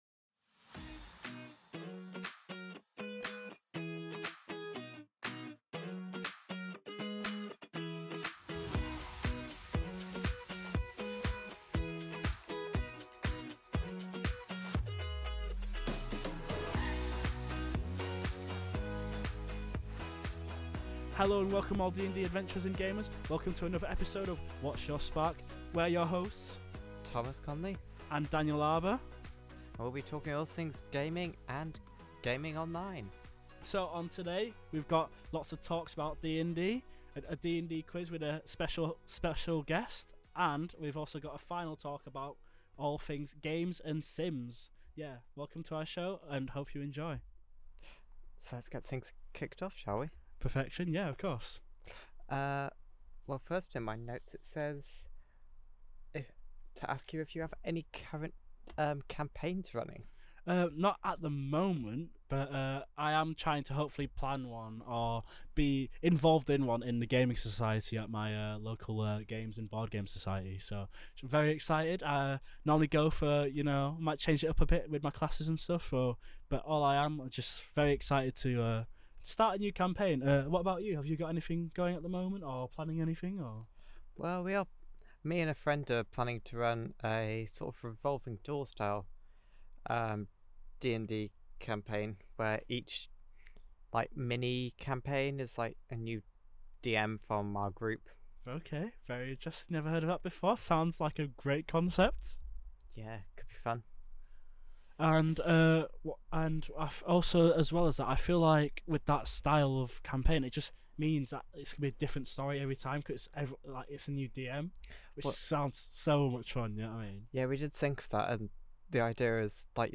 The show were students at the university of Sundeland discuss ther intrests and hobys